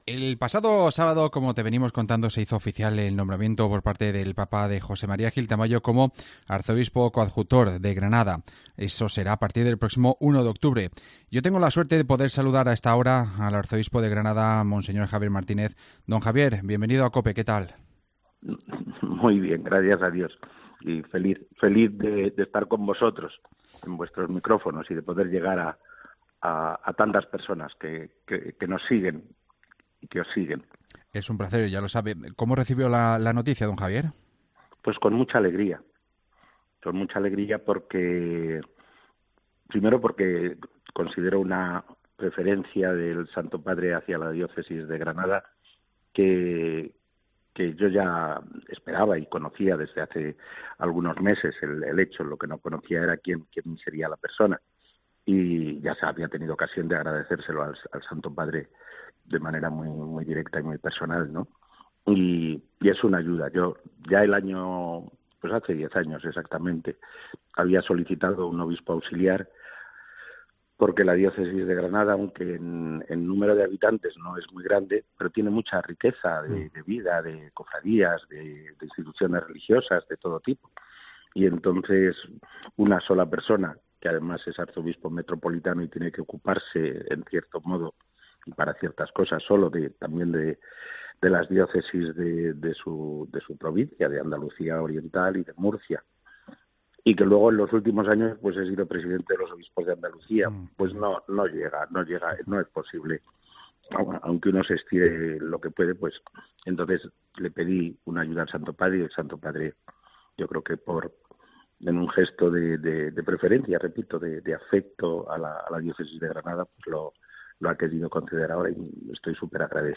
Javier Martínez nos habla ante el nombramiento de José María Gil Tamayo como arzobispo coadjutor de Granada